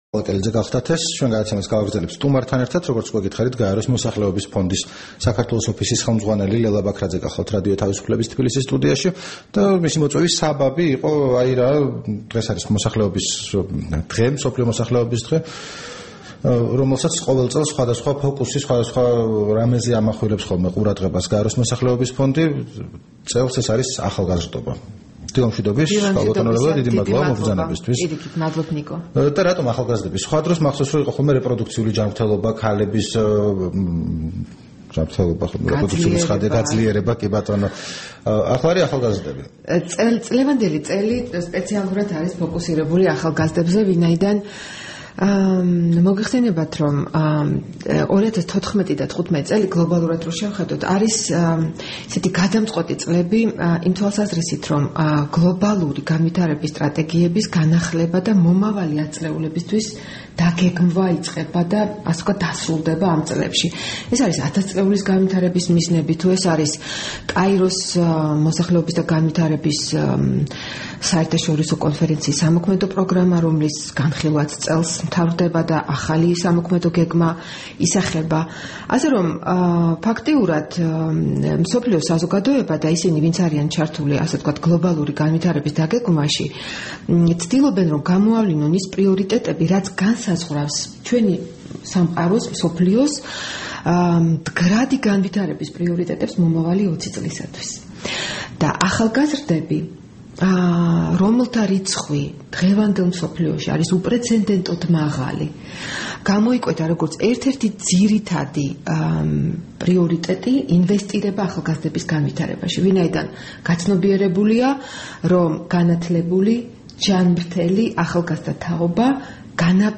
რადიო თავისუფლების თბილისის სტუდიაში